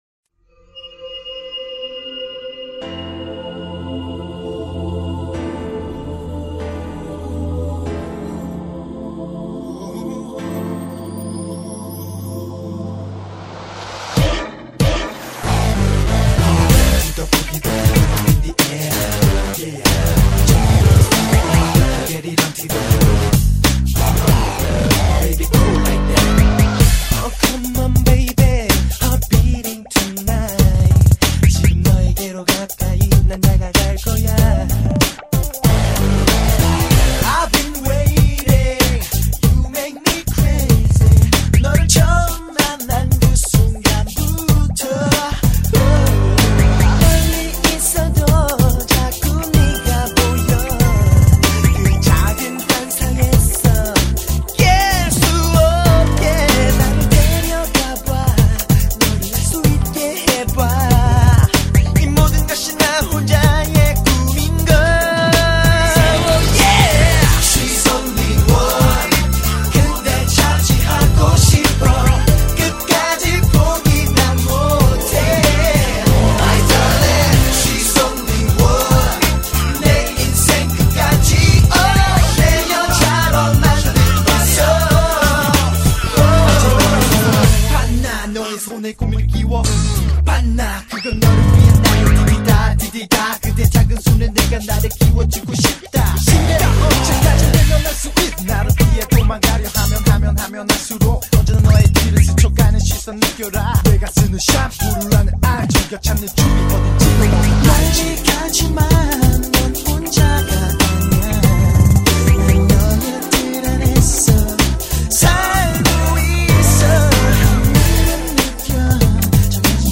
BPM142--1
Audio QualityPerfect (High Quality)